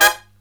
HIGH HIT12-L.wav